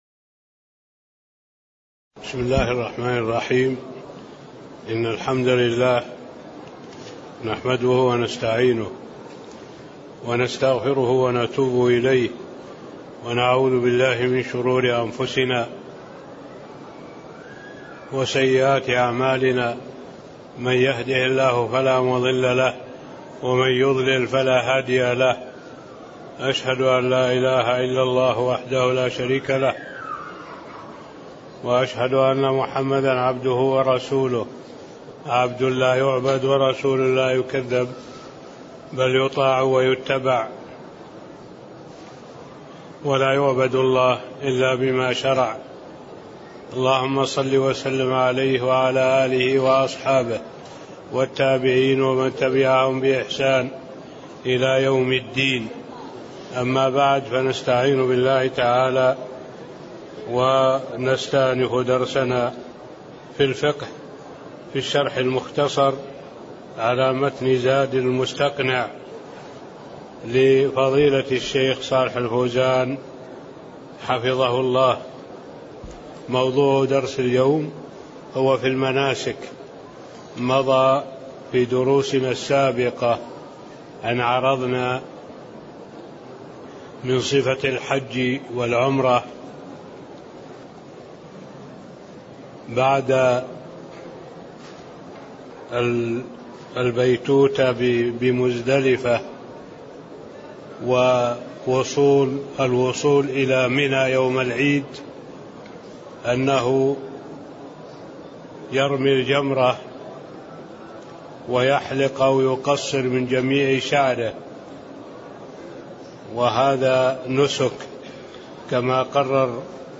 تاريخ النشر ٤ ذو القعدة ١٤٣٤ هـ المكان: المسجد النبوي الشيخ: معالي الشيخ الدكتور صالح بن عبد الله العبود معالي الشيخ الدكتور صالح بن عبد الله العبود من قوله: ثمّ يفيض إلى مكة (05)